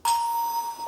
Play, download and share Family Fortunes Ding original sound button!!!!
family-fortunes-ding.mp3